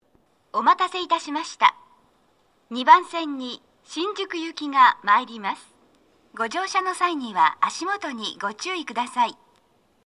鳴動は、やや遅めです。
女声
接近放送2